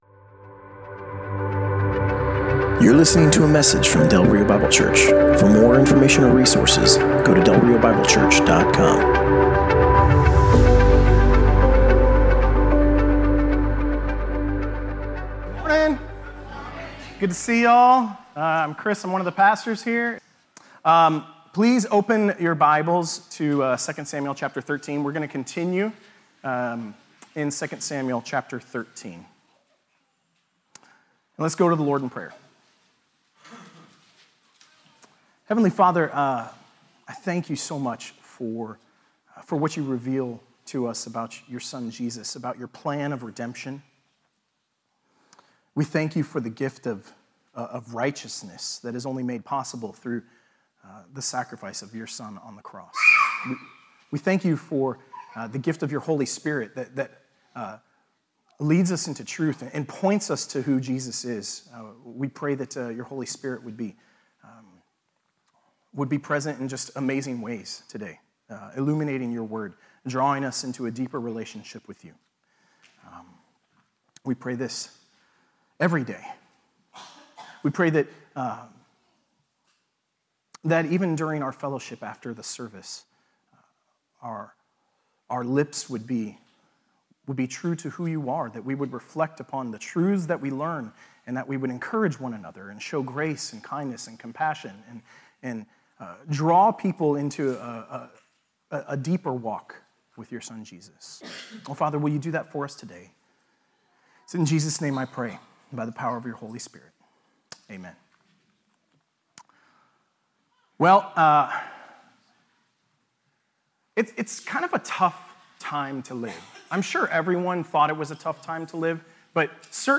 Passage: 2 Samuel 13:23 - 14:24 Service Type: Sunday Morning